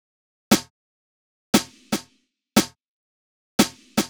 04 Snare.wav